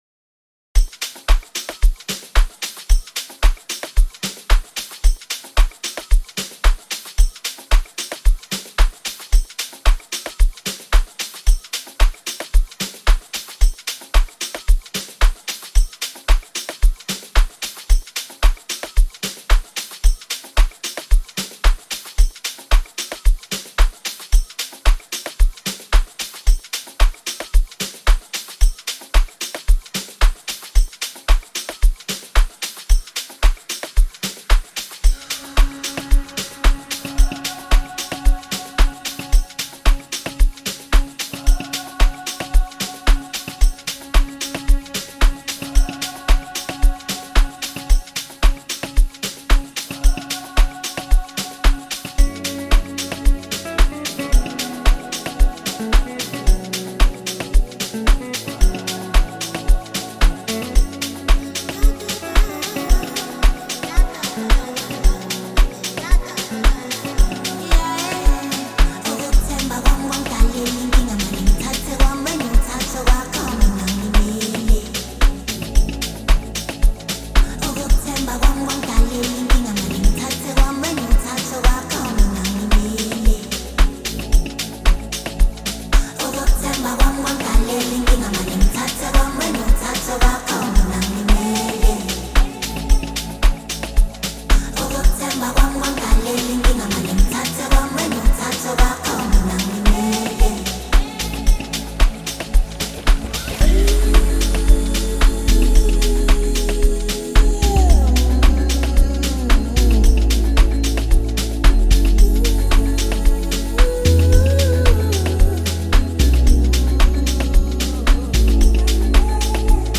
blends Afrobeat with contemporary sounds
With its high energy tempo and catchy sounds